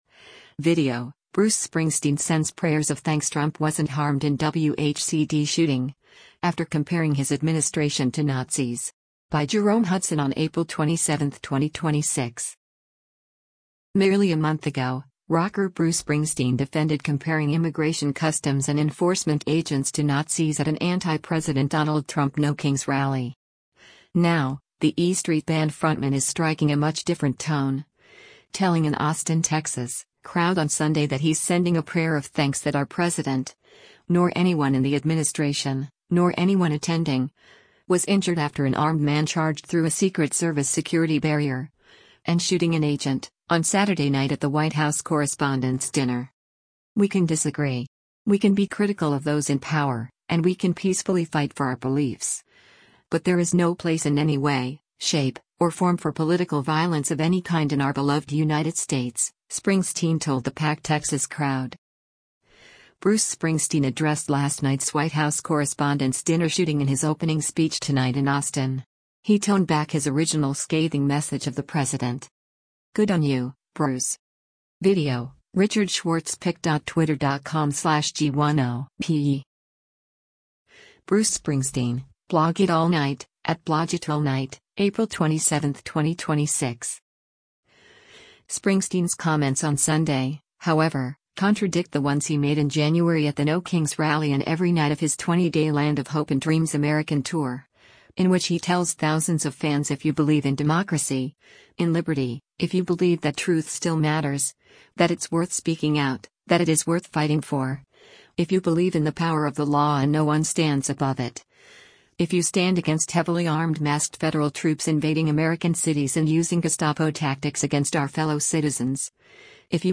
“We can disagree. We can be critical of those in power, and we can peacefully fight for our beliefs, but there is no place in any way, shape, or form for political violence of any kind in our beloved United States,” Springsteen told the packed Texas crowd.